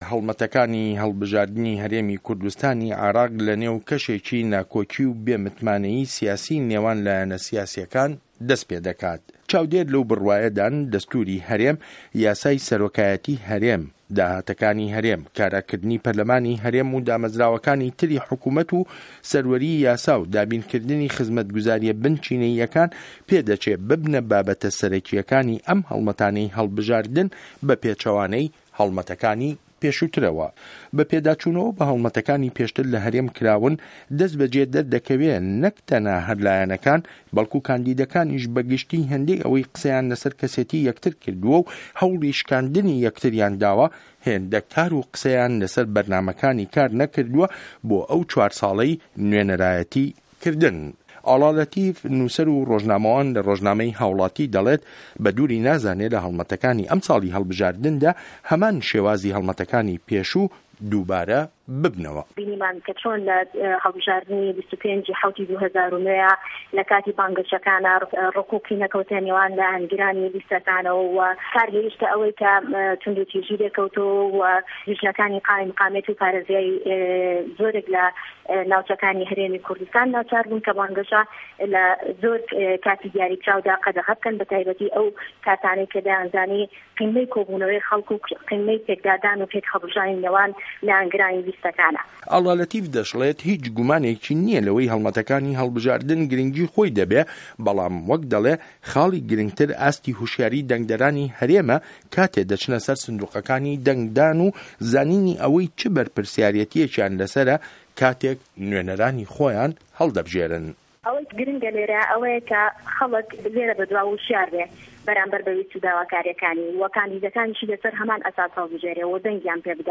ڕاپۆرتی هه‌ڵمه‌ته‌کانی هه‌ڵبژاردن بۆ ناساندن یان بۆ ناوزڕاندن